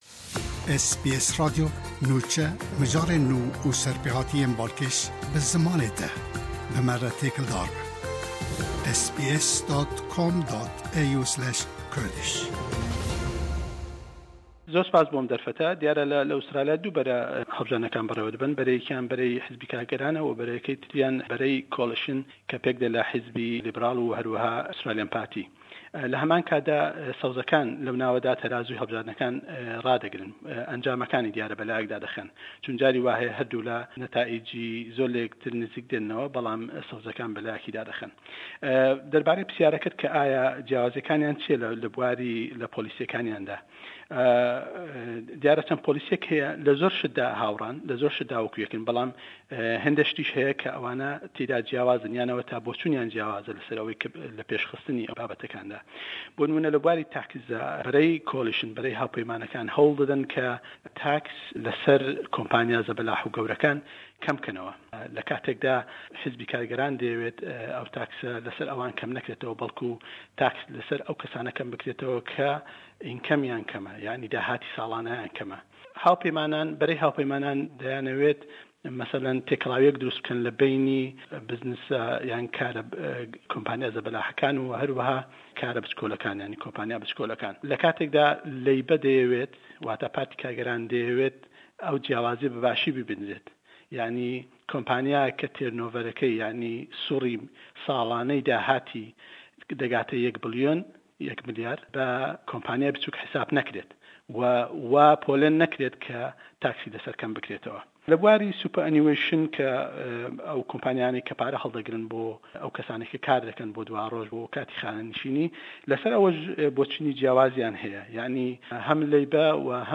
Kampîn kirdin bo hellbijartinekanî federalî Australya le layen parte siyasêkanewe berdewame bo rojî dengdan le 2î mangî 7 da. Le em beşey yekemî hevpeyvîn-man le gell rojnemewan (û xwêndkarî diktora)